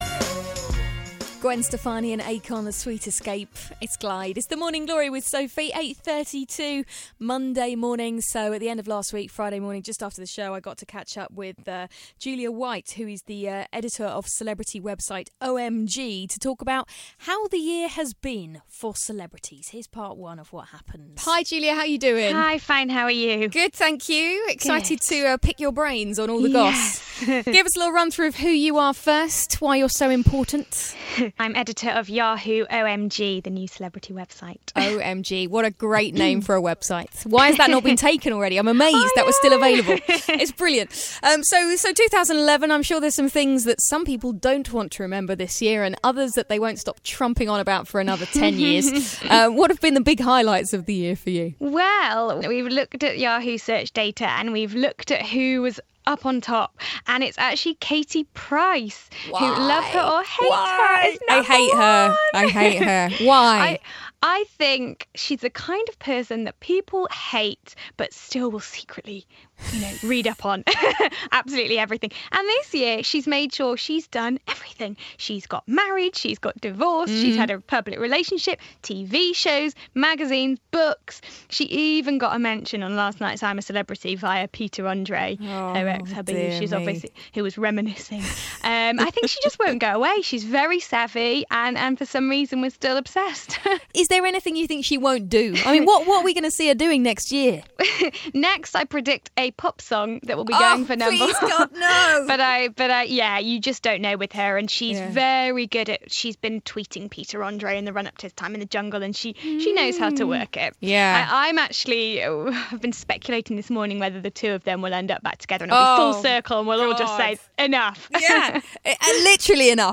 Glide's Morning Glory Interview